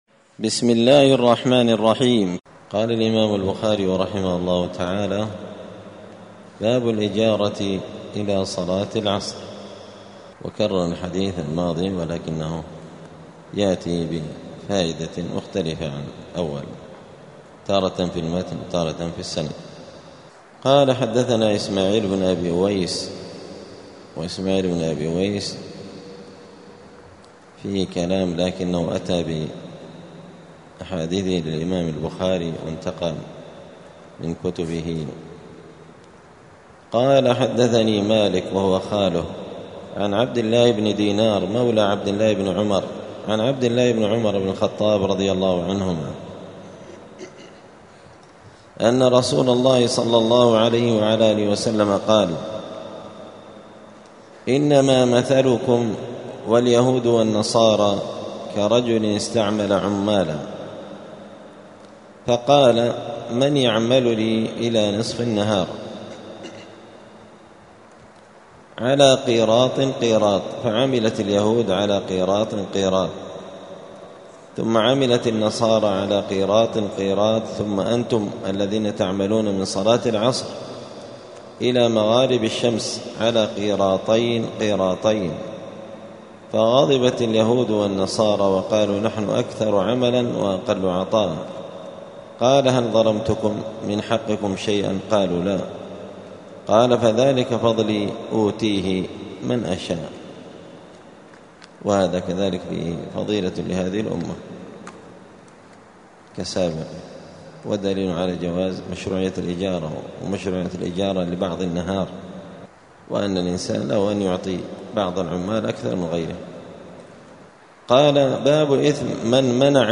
دار الحديث السلفية بمسجد الفرقان قشن المهرة اليمن
الأحد 11 محرم 1447 هــــ | الدروس، دروس الحديث وعلومه، شرح صحيح البخاري، كتاب الإجارة من صحيح البخاري | شارك بتعليقك | 9 المشاهدات